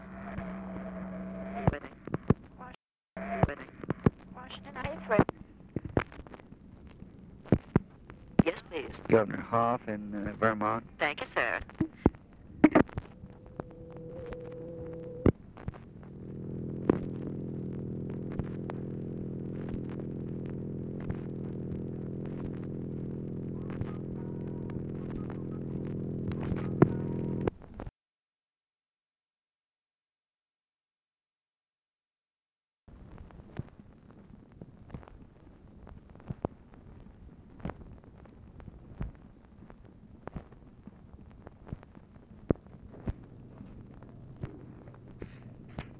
Conversation with TELEPHONE OPERATOR, November 30, 1963